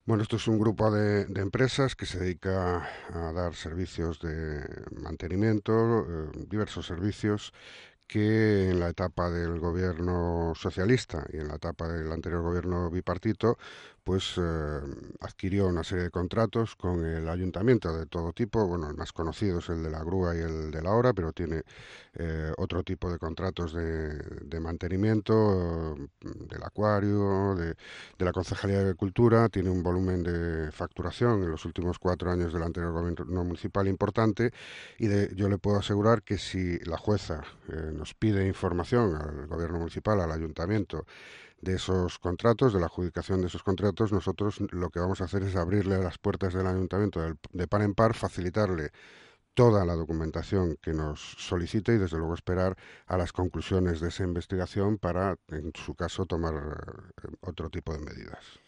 El pasado 21 de septiembre el primer teniente de alcalde Julio Flores explicó en Radiovoz los contratos de Vendex en el concello A Coruña.